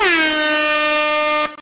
Swing Woosh
air arm attack audio bull bullwhip cc0 effect sound effect free sound royalty free Memes